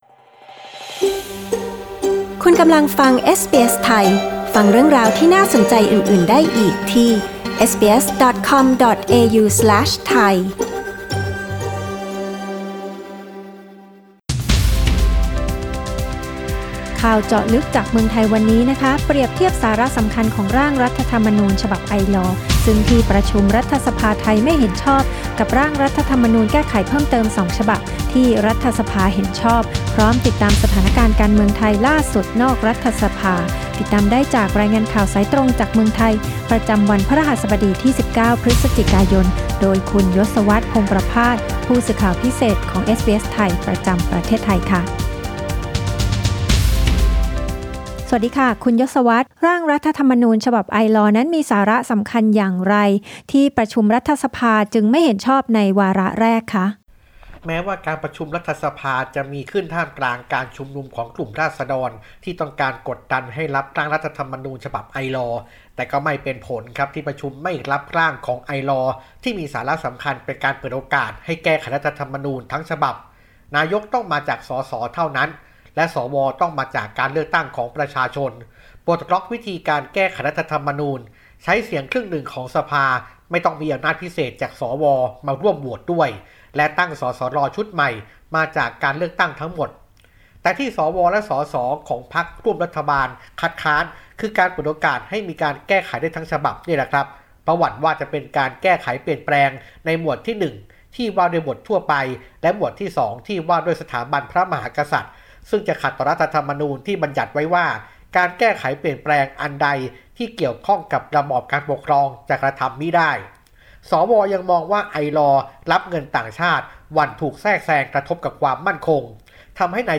รายงานข่าวสายตรงจากเมืองไทย จากเอสบีเอส ไทย Source: Pixabay